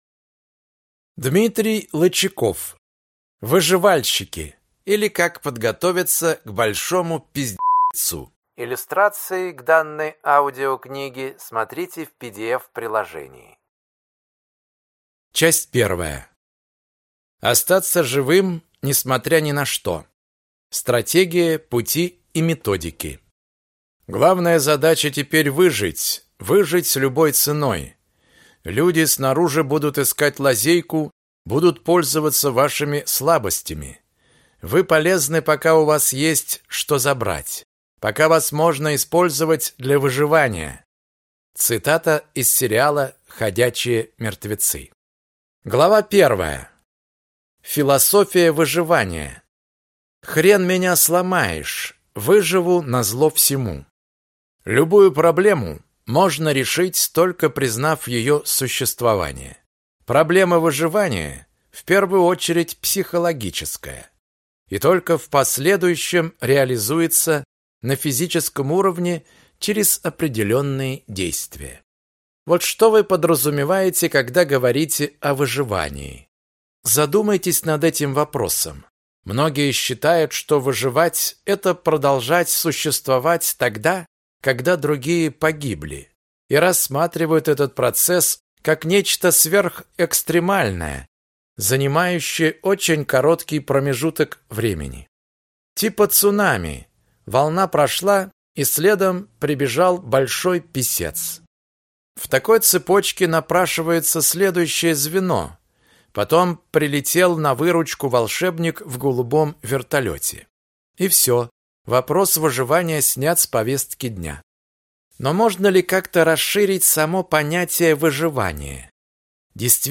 Аудиокнига Выживальщики, или Как подготовиться к Большому П**цу | Библиотека аудиокниг